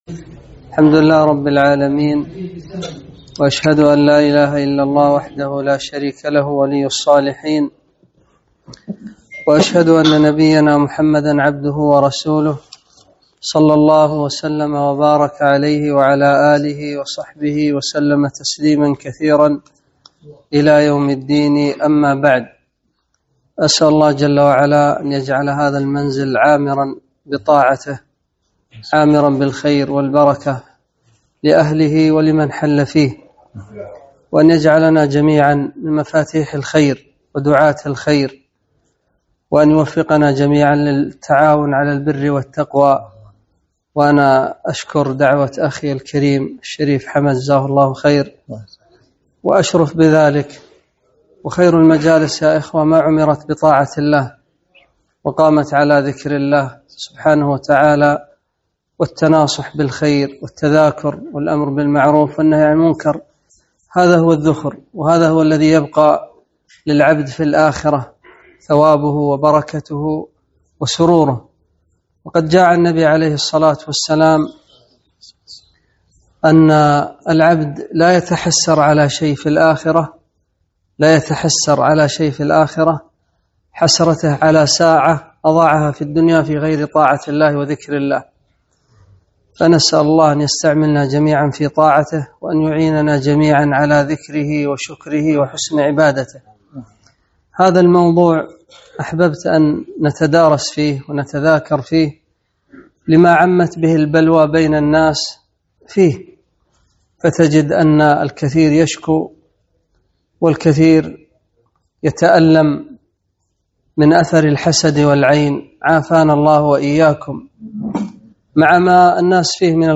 محاضرة - العين حق وسبل الوقاية والعلاج